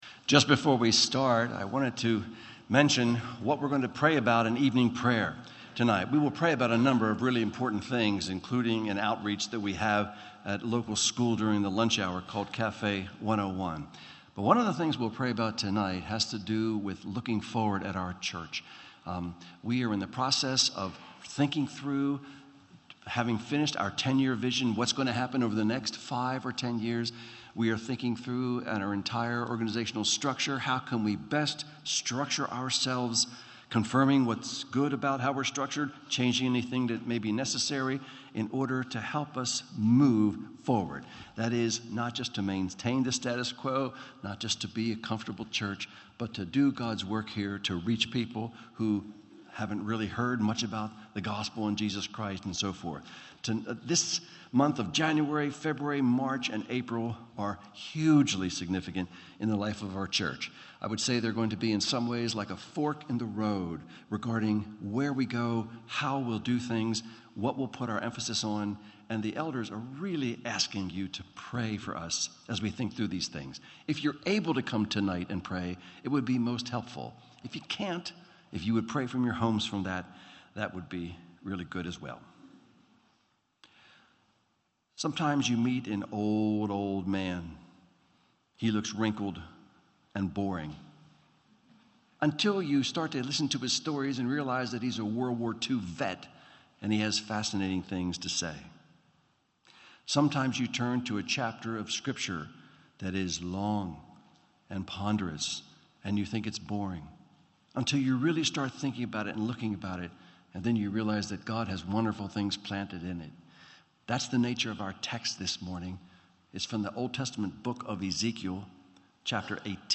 Ezekiel — Audio Sermons — Brick Lane Community Church